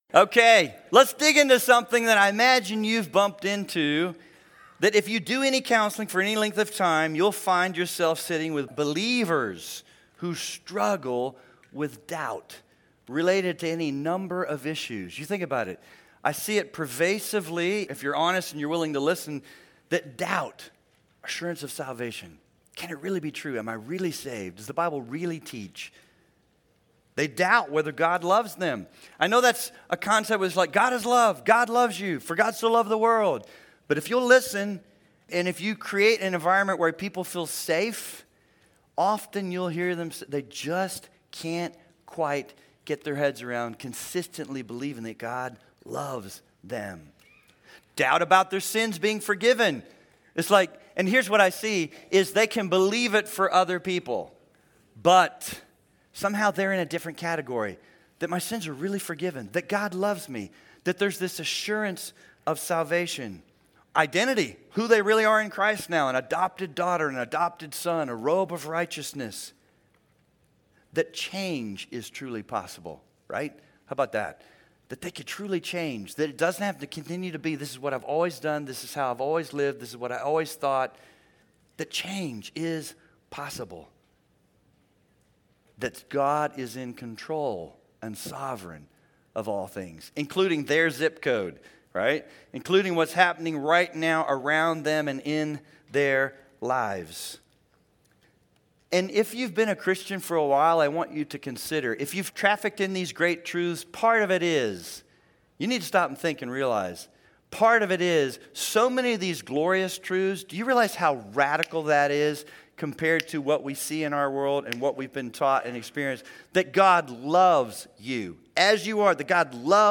This is a session from the Biblical Counseling Training Conference hosted by Faith Church in Lafayette, Indiana.
You may listen to the first 10 minutes of this session by clicking on the "Preview Excerpt" button above.